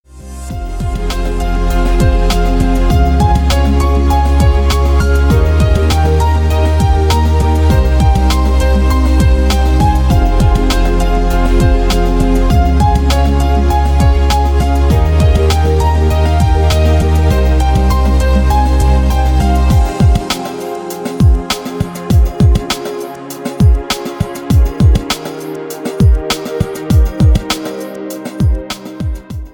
• Качество: Хорошее
• Категория: Красивые мелодии и рингтоны